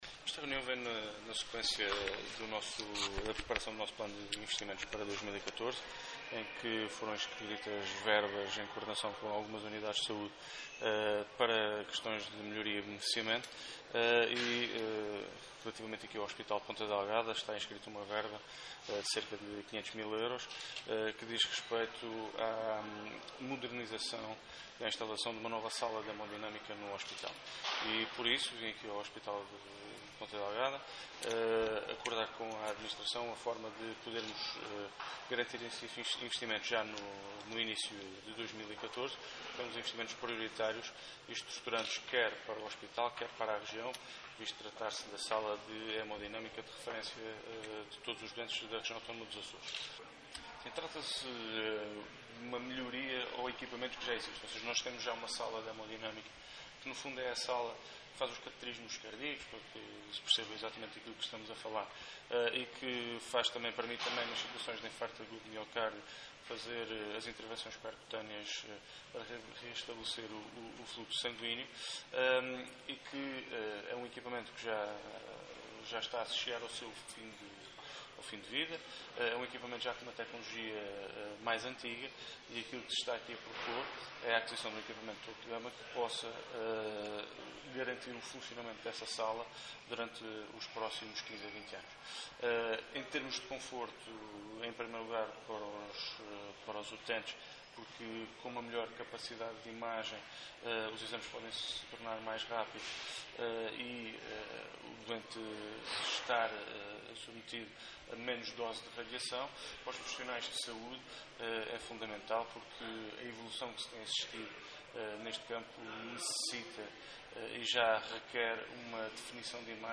Luís Cabral falava aos jornalistas à saída de uma reunião com o Conselho de Administração daquela unidade de saúde, que serviu para afinar estratégicas no sentido de instalar este equipamento já no início de 2014.